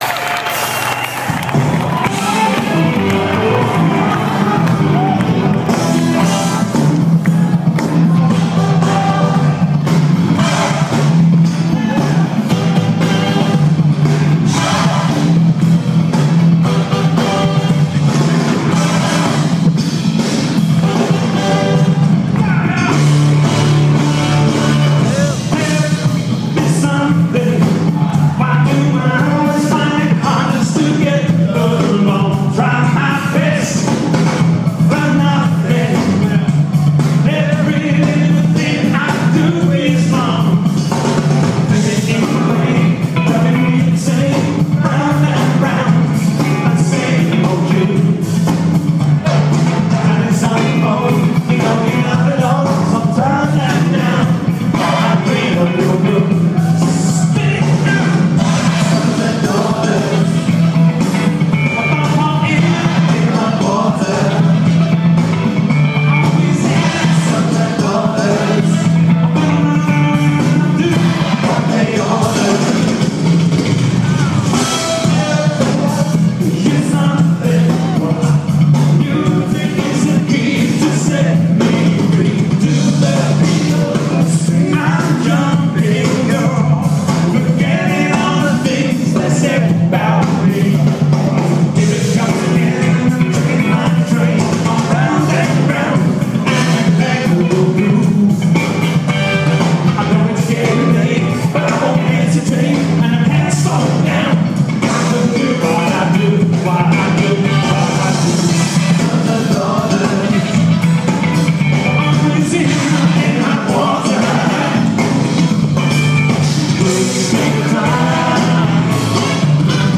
This is the longest version of this live track I've heard.